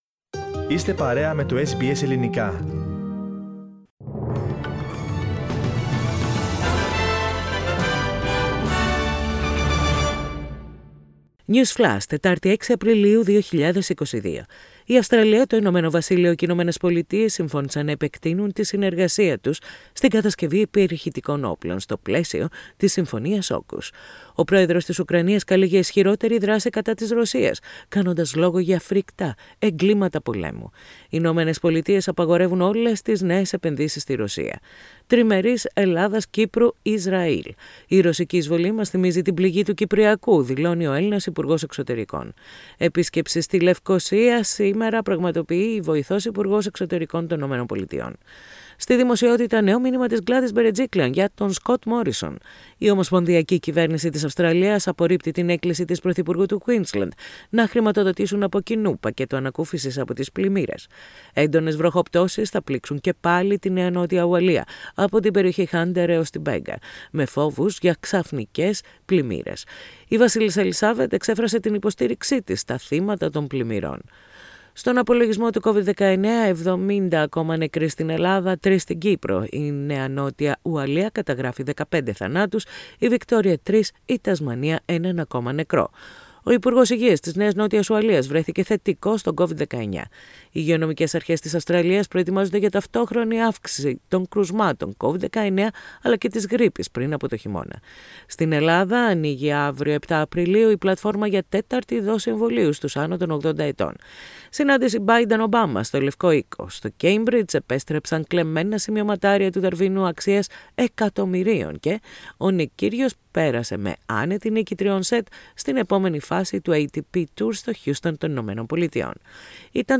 News Flash - Σύντομο Δελτίο Ειδήσεων - Τετάρτη 6.4.22